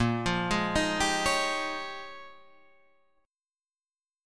A#m13 chord